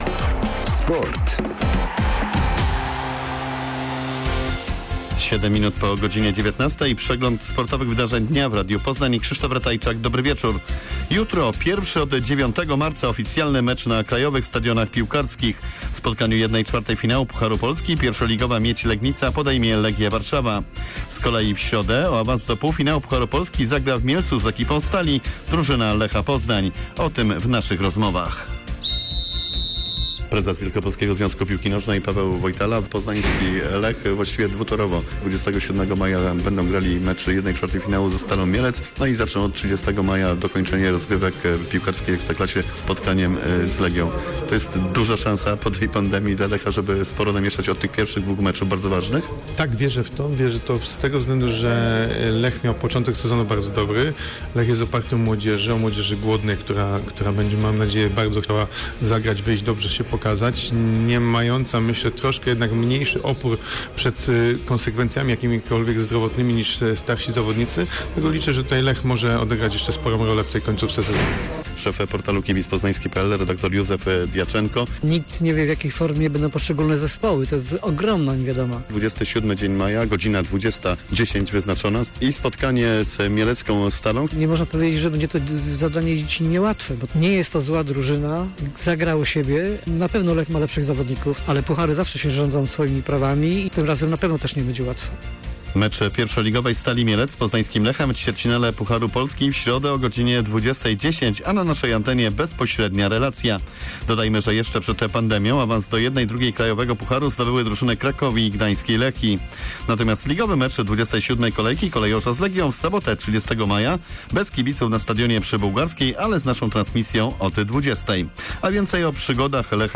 SERWIS SPORTOWY